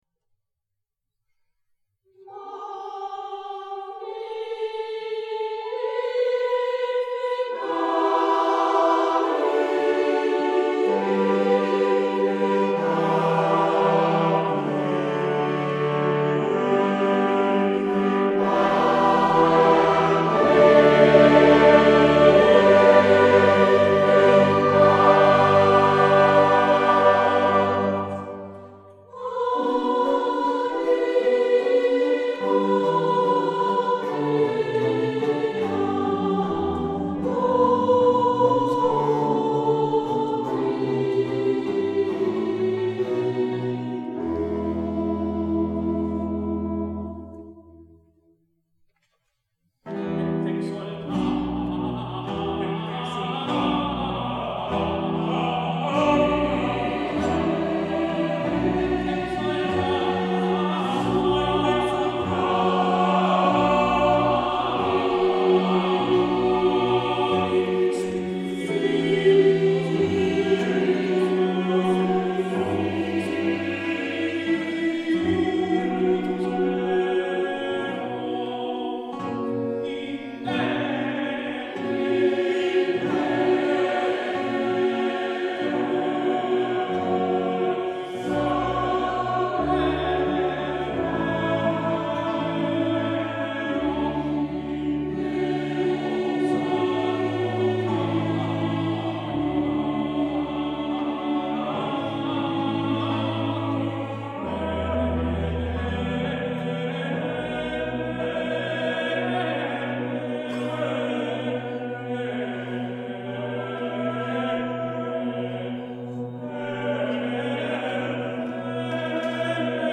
For eight soloists, two choirs and orchestra.
Maria Oratororio Choir
Accompanied by Die Buxtehunde
Sankta Maria Church, Helsingborg
A recurring feature of the work is a plainchant theme as a background to ornamentation by other parts.